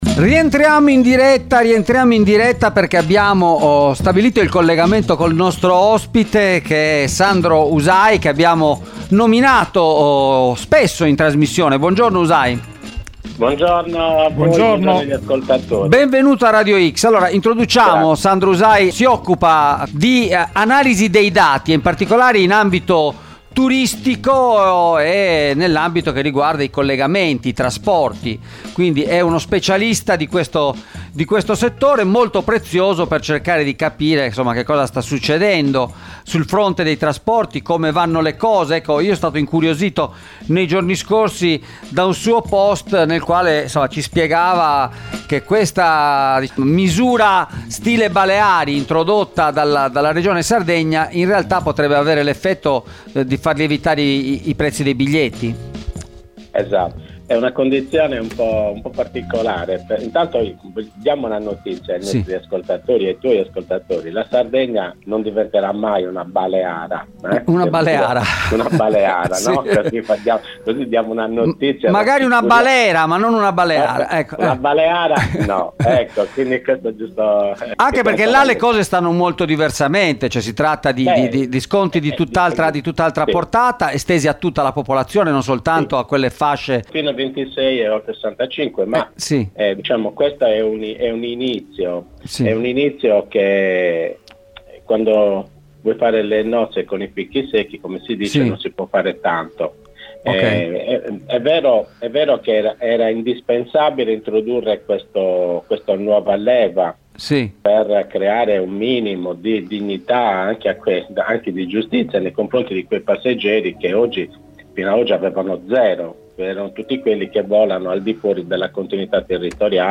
è intervenuto questa mattina ai microfoni di Radio X per segnalare le anomalie della nuova continuità territoriale approvata dalla Regione: